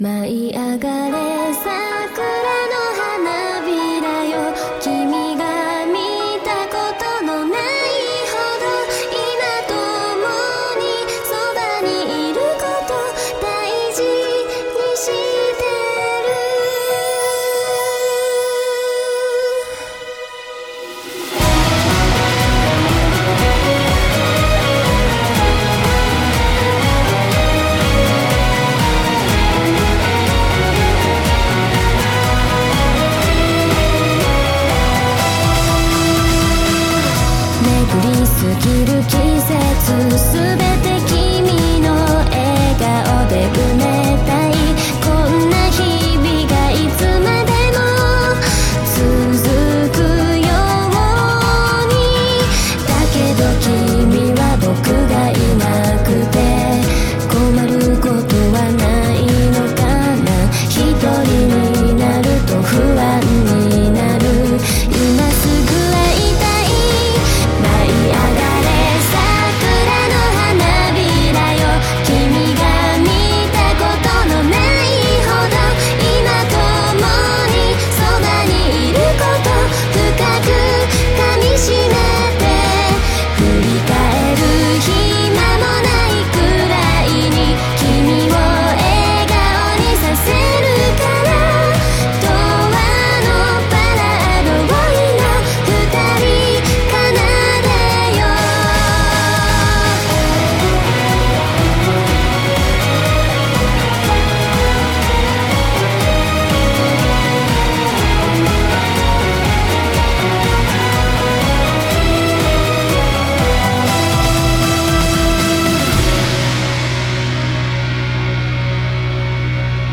BPM122
Audio QualityPerfect (High Quality)